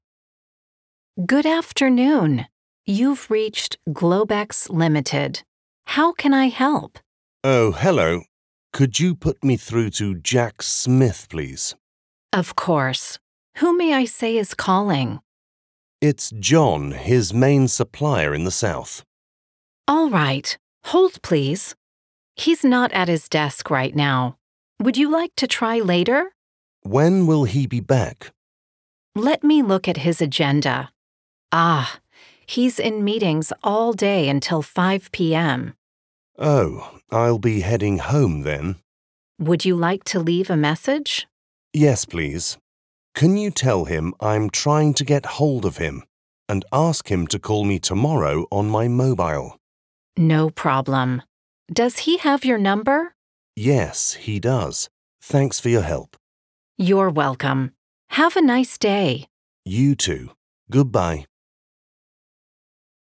Speaker (UK accent)
Speaker (American accent)